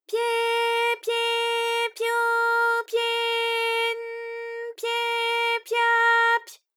ALYS-DB-001-JPN - First Japanese UTAU vocal library of ALYS.
pye_pye_pyo_pye_n_pye_pya_py.wav